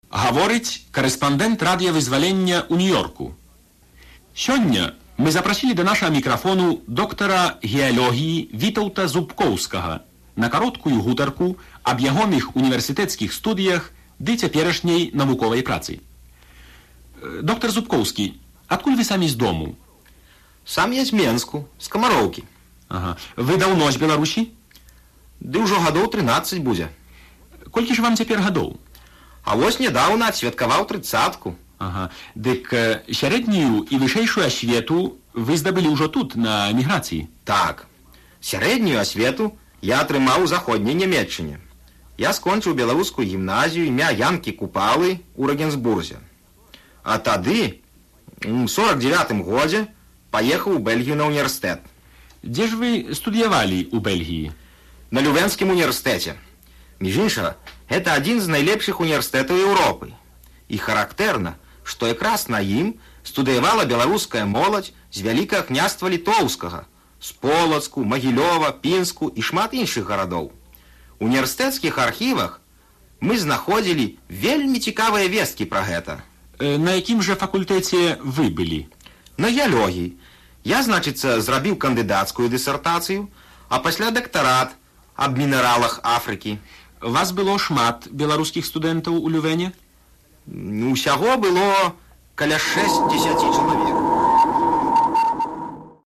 А зараз — зноў наш архіў.